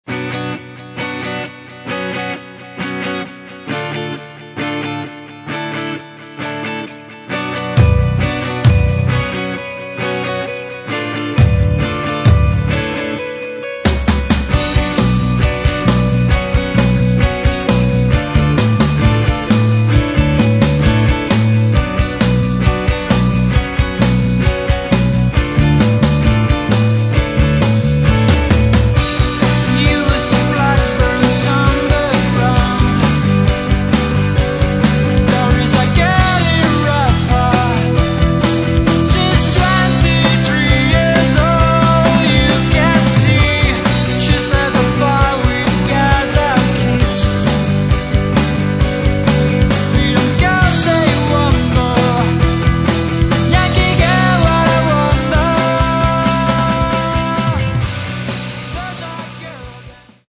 Metal
вокал, гитары
бас
ударные